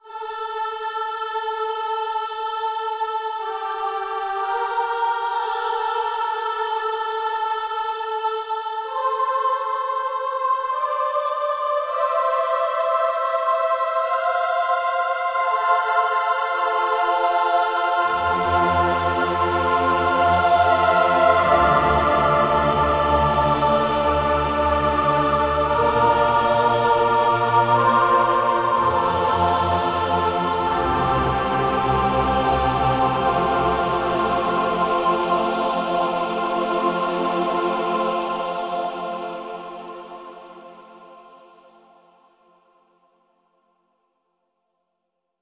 Here's 4 free program sounds powered by choir samples.
free-choir3.mp3